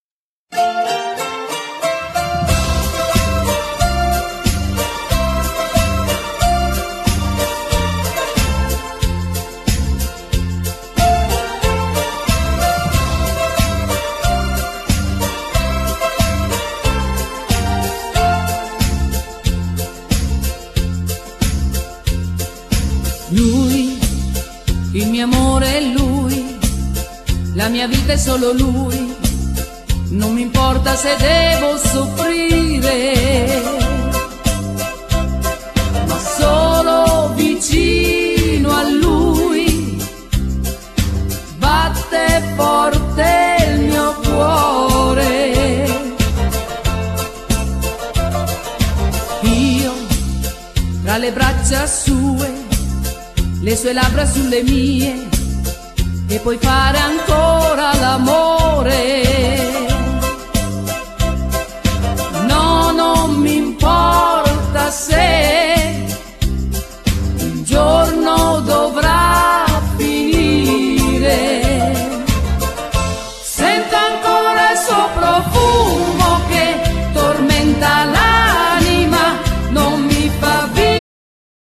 Genere : Liscio folk